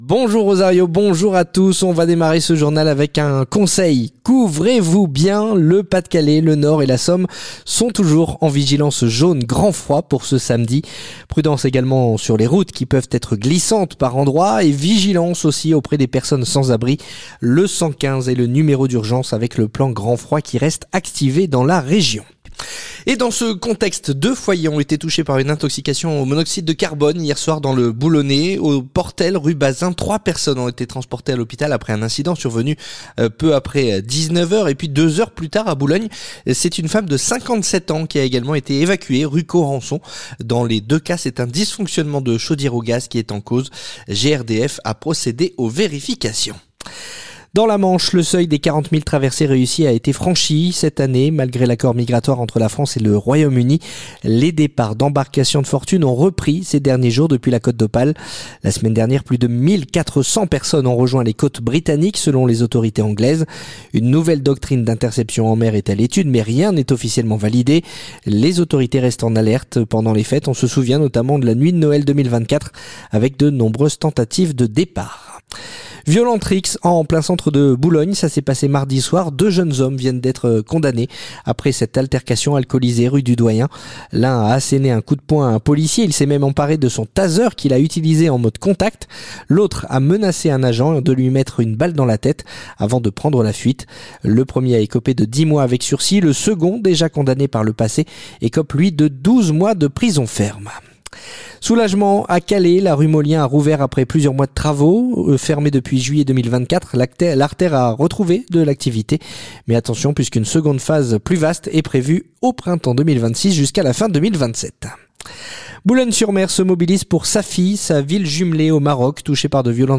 Le journal du samedi 27 décembre